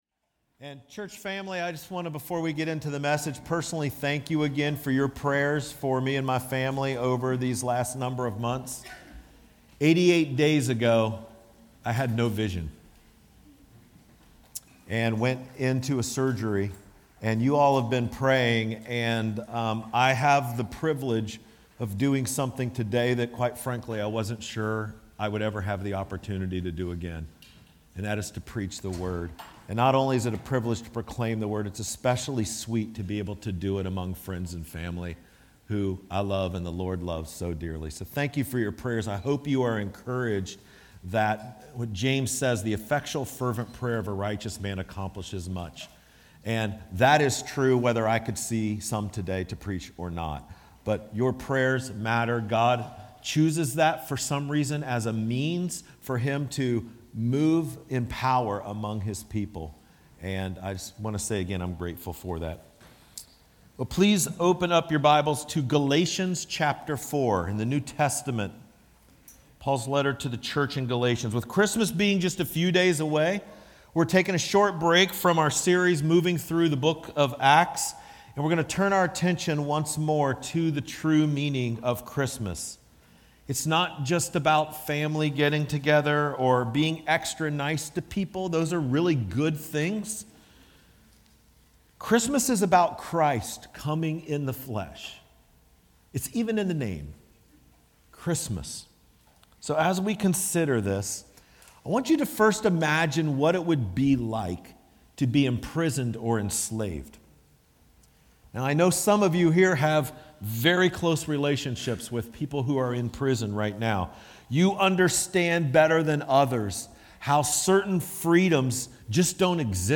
These sermons have been preached outside a normal sermon series at Risen Hope.